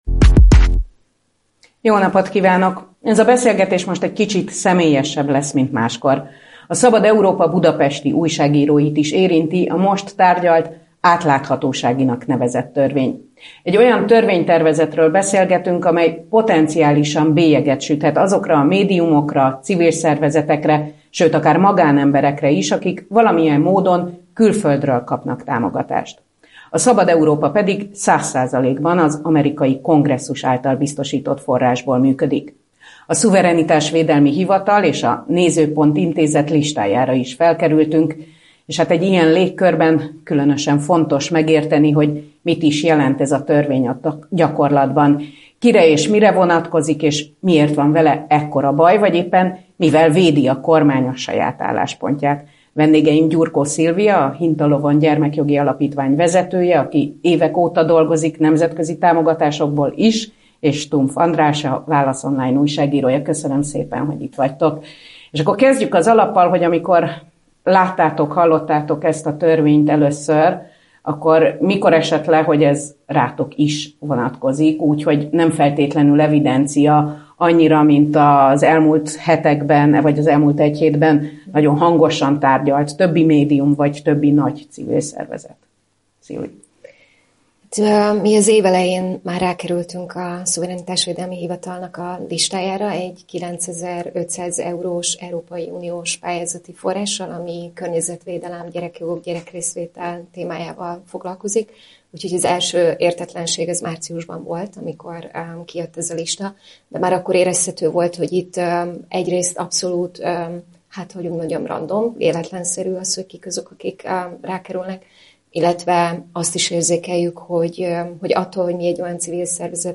Az atláthatóságinak nevezett törvény a civilekre, a független médiára, kutatókra is célkeresztet rajzol. Hogyan lesz a jogból politikai fegyver, és mit lehet tenni ellene? Ezekről a kérdésekről beszélgetünk